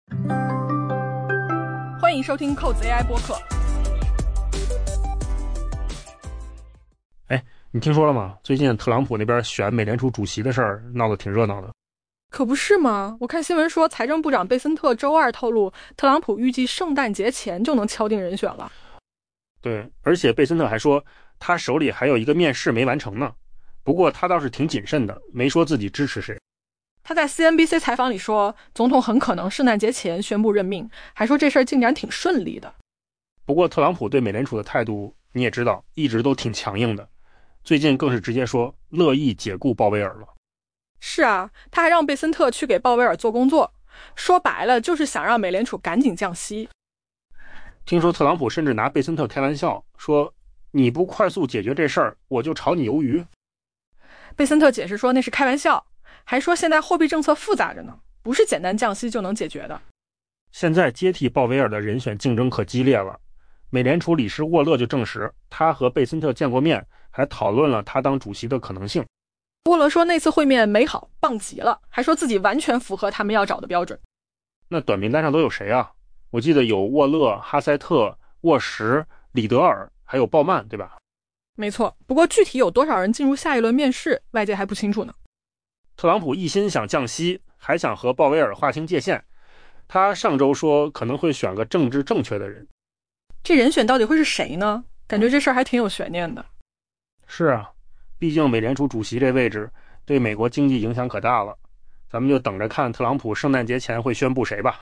AI播客：换个方式听新闻 下载mp3
音频由扣子空间生成音频由扣子空间生成